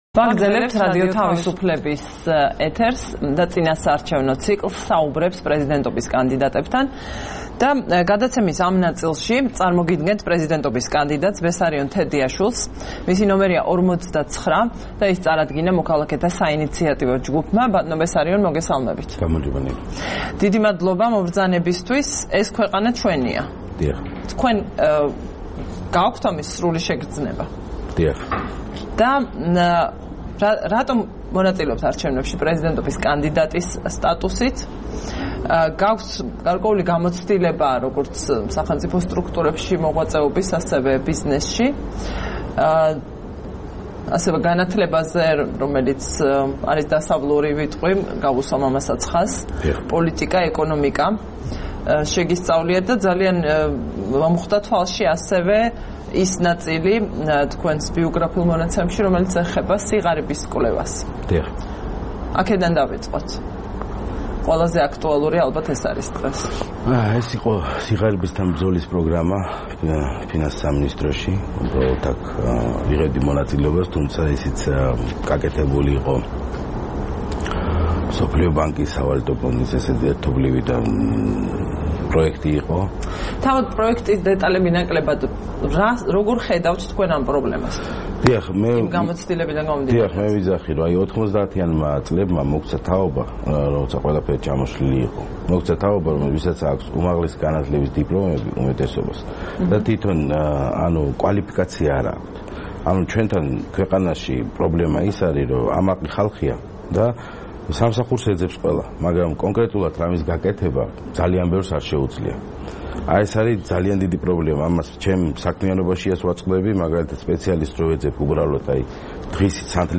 საუბარი პრეზიდენტობის კანდიდატთან